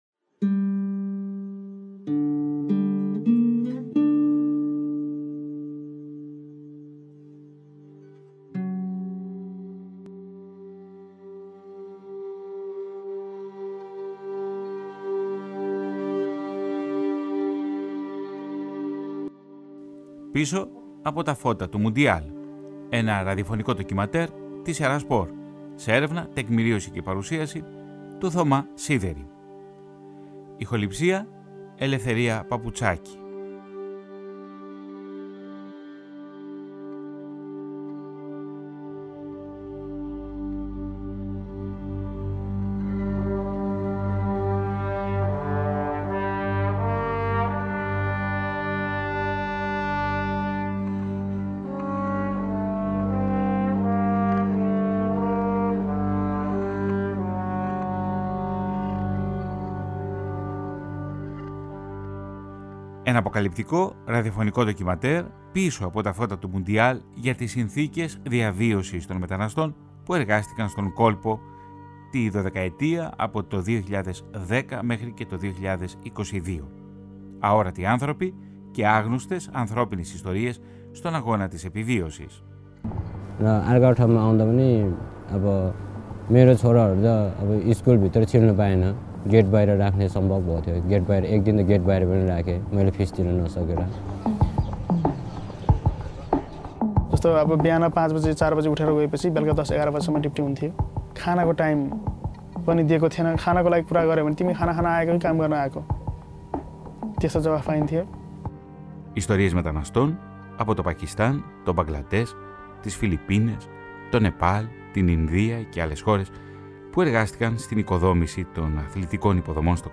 Καθώς το παγκόσμιο κύπελλο ποδοσφαίρου βρίσκεται σε εξέλιξη στο Κατάρ, το ραδιοφωνικό ντοκιμαντέρ ρίχνει φως σε άγνωστες ιστορίες μεταναστών εργατών που εργάστηκαν στη χώρα του Κόλπου τα τελευταία 12 χρόνια κάτω από εξαιρετικά δύσκολες και σκληρές συνθήκες εργασίας.